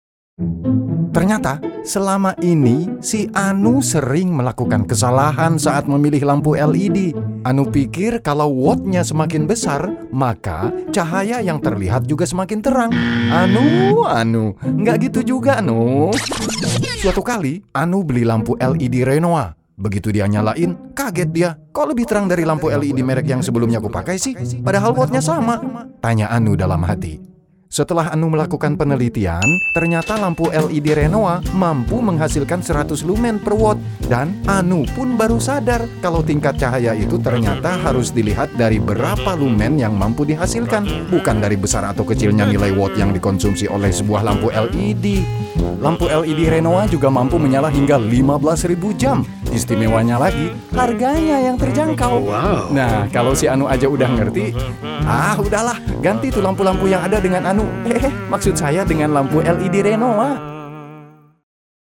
Commercial, Deep, Natural, Versatile, Corporate
Commercial
Many people say that his voice is distinctive, strongly masculine, and pleasant to listen to.